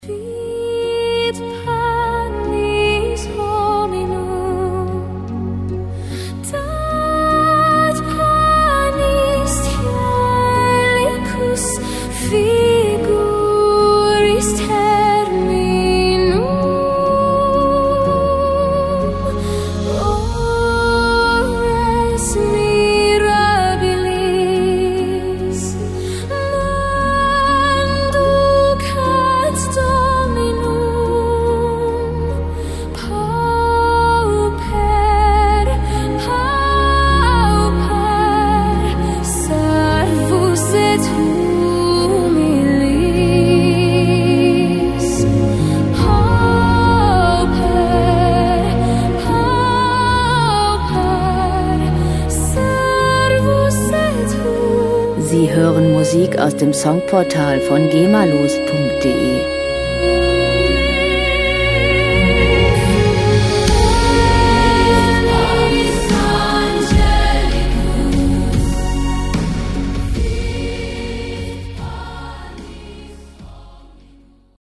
Musikstil: Celtic Crossover
Tempo: 74 bpm
Tonart: Fis-Dur
Charakter: episch, innig